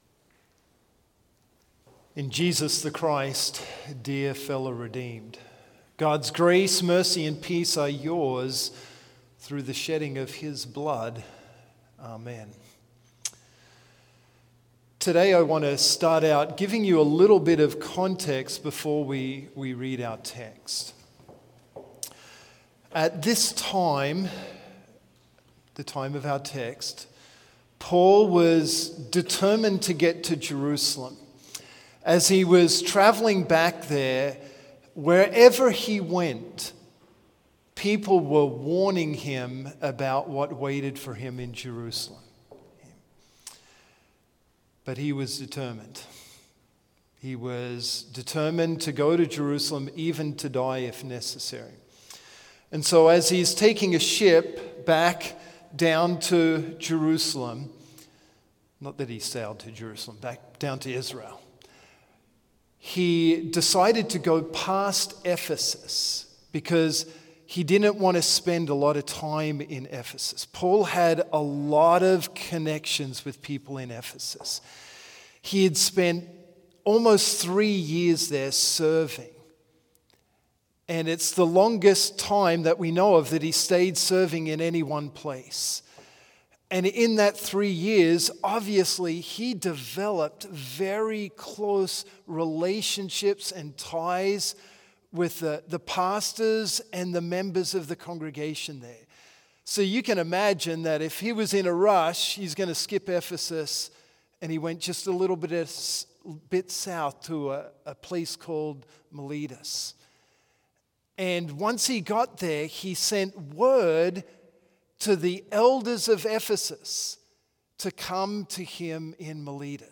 Complete service audio for Chapel - April 28, 2023